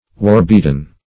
war-beaten - definition of war-beaten - synonyms, pronunciation, spelling from Free Dictionary Search Result for " war-beaten" : The Collaborative International Dictionary of English v.0.48: War-beaten \War"-beat`en\, a. Warworn.